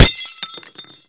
glass2.wav